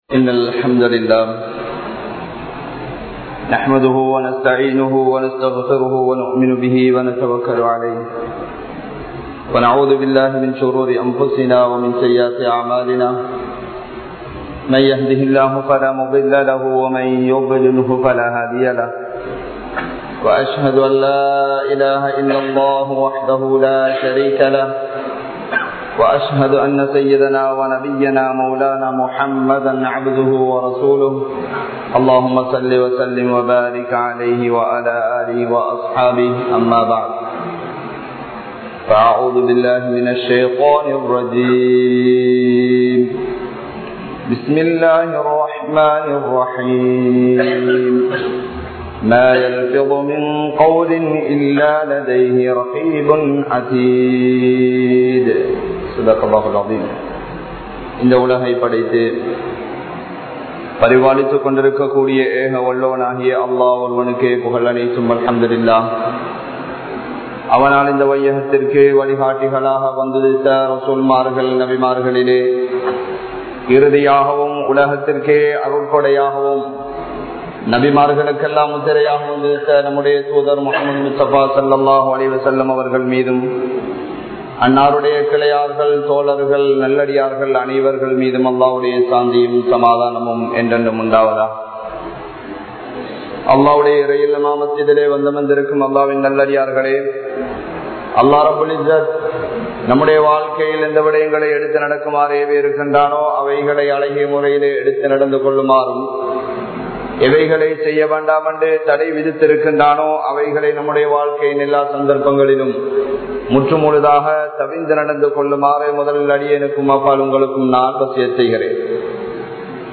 Naavai Paathuhaapoam (நாவை பாதுகாப்போம்) | Audio Bayans | All Ceylon Muslim Youth Community | Addalaichenai
Jumua Masjidh